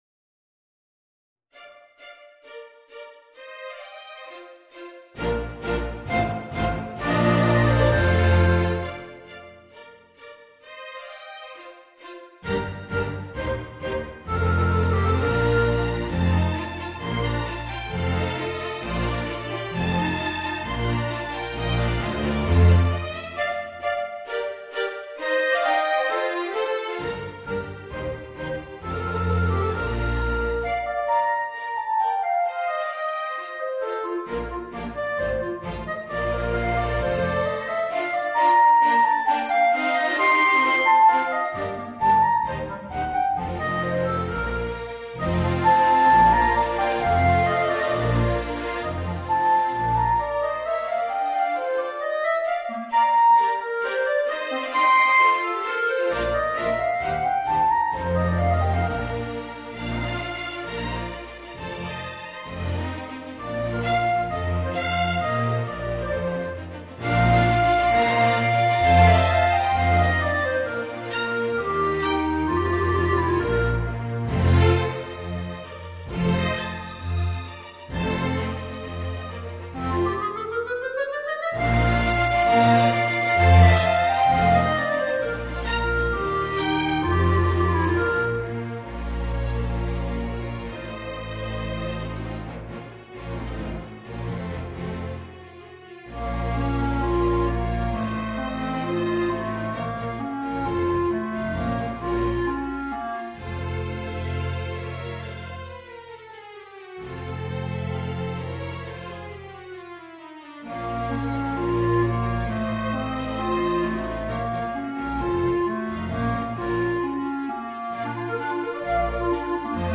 Voicing: Clarinet and String Orchestra